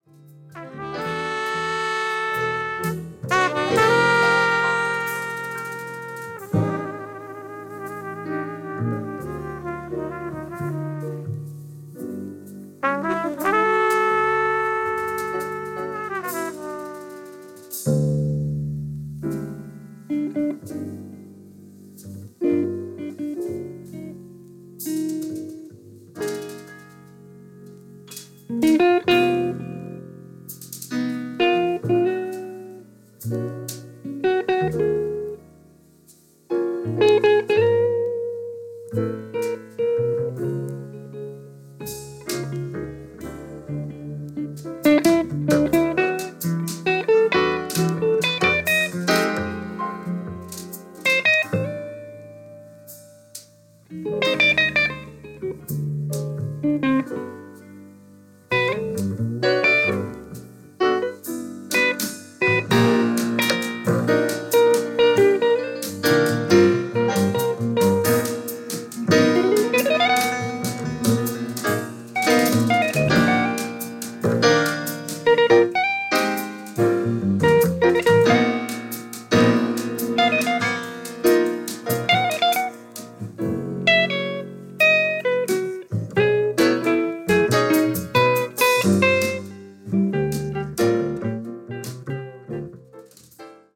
Piano
Saxophone
Drums
Bass
Trumpet